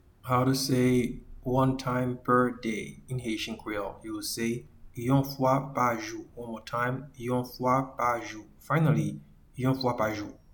Pronunciation:
One-time-per-day-in-Haitian-Creole-Yon-fwa-pa-jou.mp3